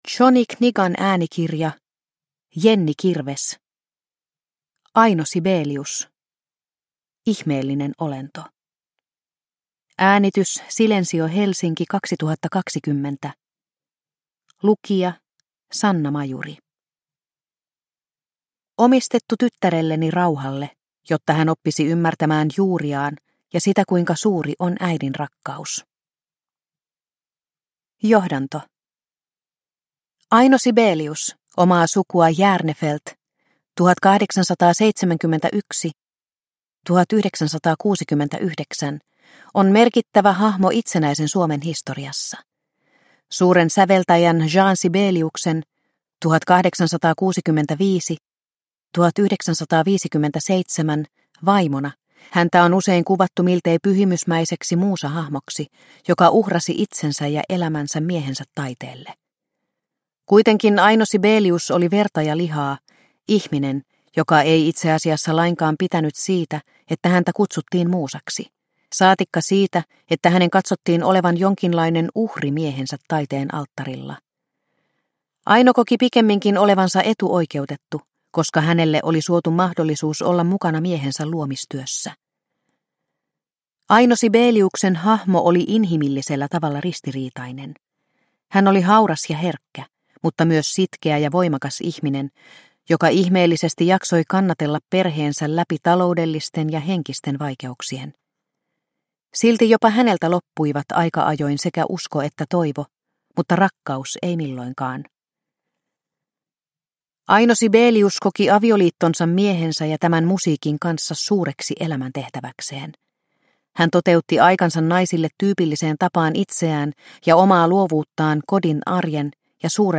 Aino Sibelius - Ihmeellinen olento – Ljudbok – Laddas ner